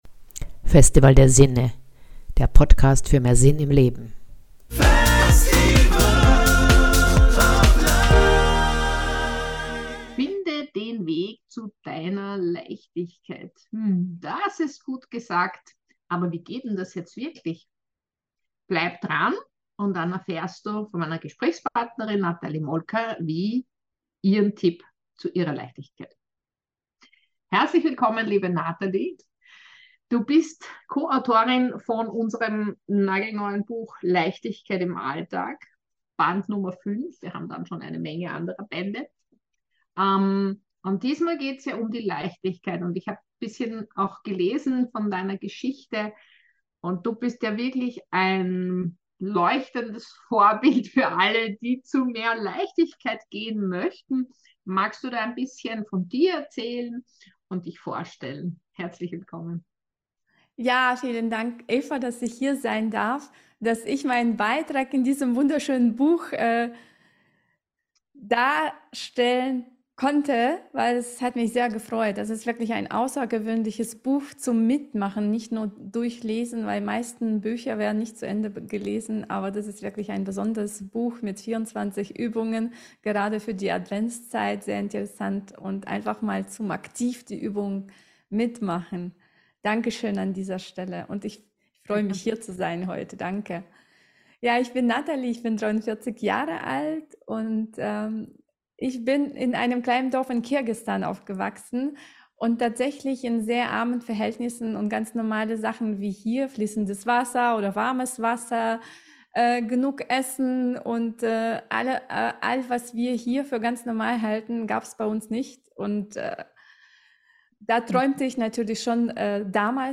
Dieses Interview ist ein Vorgeschmack auf das Buch "Leichtigkeit im Alltag“ das 24 Übungen enthält, um mehr Leichtigkeit in dein Leben zu bringen.